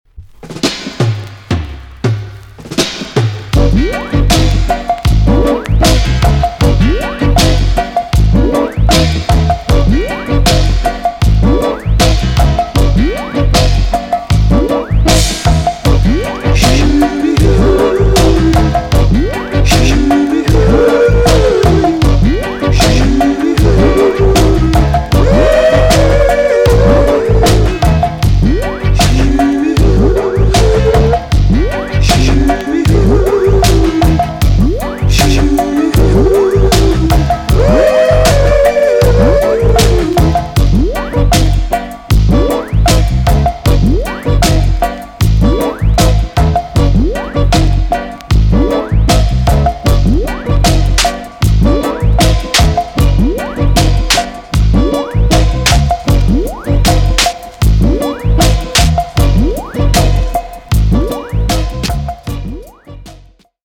TOP >DISCO45 >80'S 90'S DANCEHALL
EX- 音はキレイです。